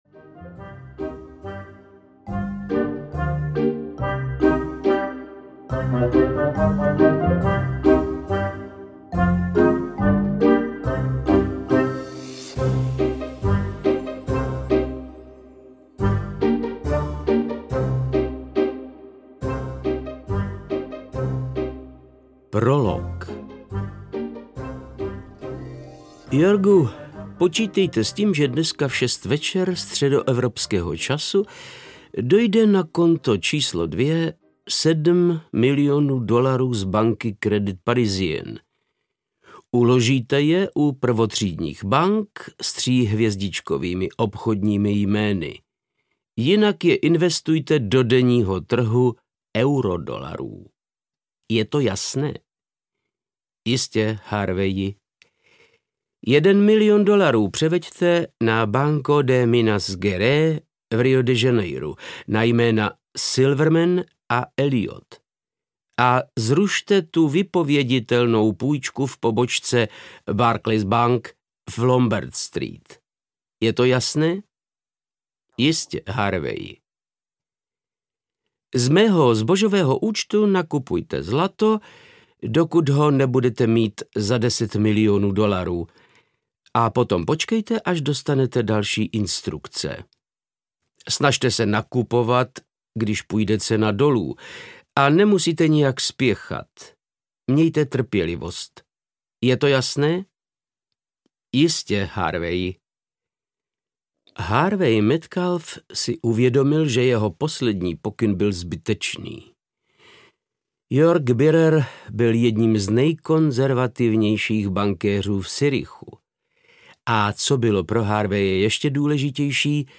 Ani o dolar víc, ani o dolar míň audiokniha
Ukázka z knihy
• InterpretVáclav Knop